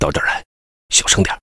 文件 文件历史 文件用途 全域文件用途 Bk2_tk_05.ogg （Ogg Vorbis声音文件，长度1.4秒，94 kbps，文件大小：16 KB） 源地址:游戏语音 文件历史 点击某个日期/时间查看对应时刻的文件。